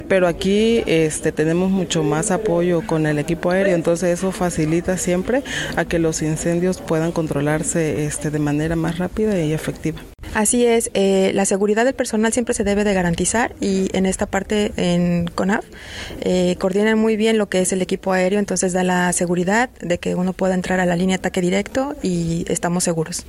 Entre los combatientes, destacó la presencia de dos mujeres que conformaron las brigadas que se sumaron al trabajo tanto en primera linea de ataque al fuego como en labores preventivas